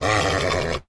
sounds / mob / horse / angry1.ogg
angry1.ogg